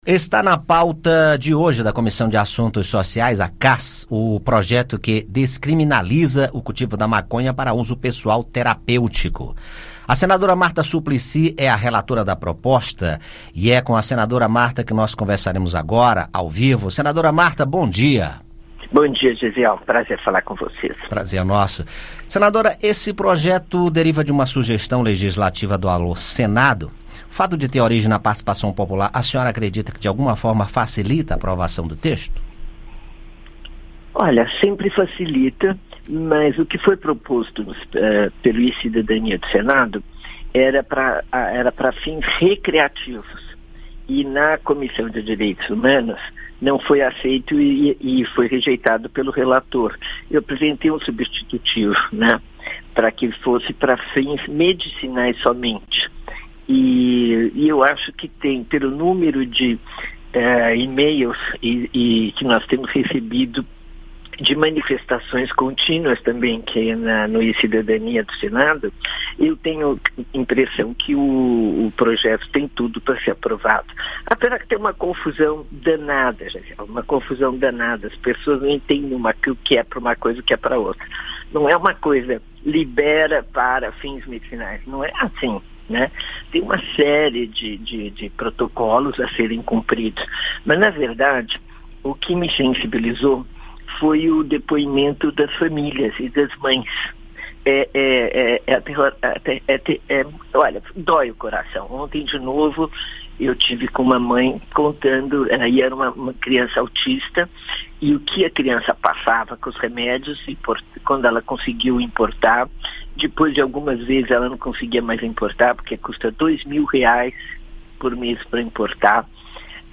A senadora Marta Suplicy (MDB-SP) é a relatora da proposta e fala sobre o assunto.